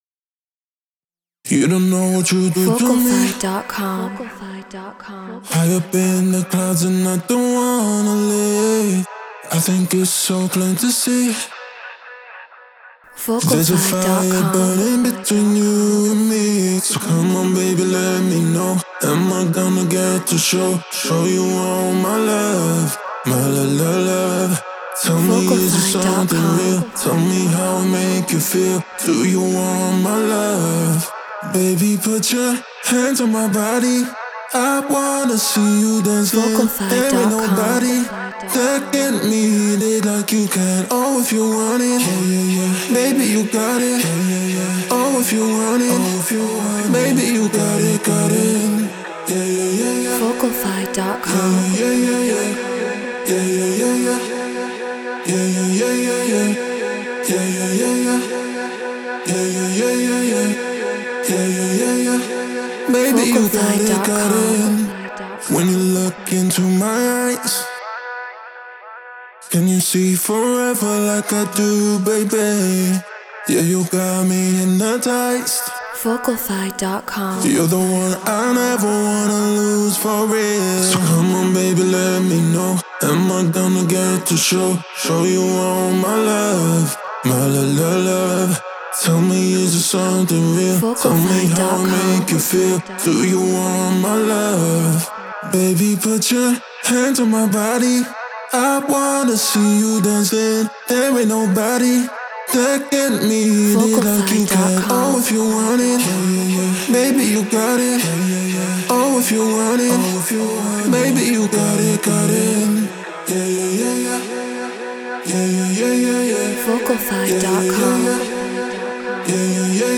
Afro House 120 BPM F#min
Neumann TLM 103 Focusrite Scarlett Pro Tools Treated Room